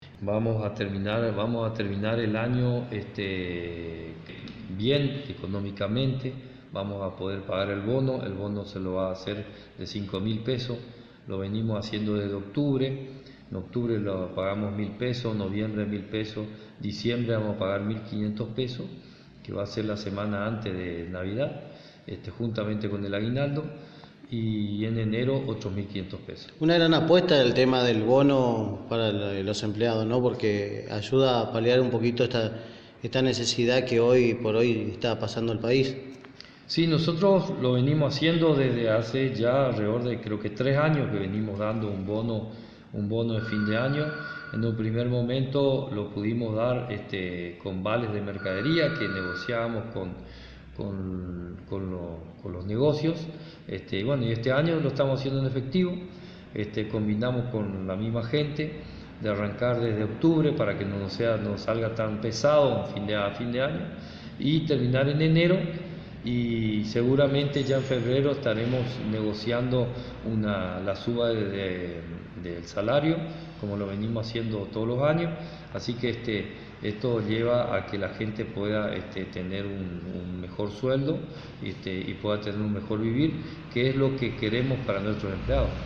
De esta manera el Intendente Municipal Carlos Alberto Pernigotti, nos comentaba como cierra el año el Municipio de Concepción de la Sierra.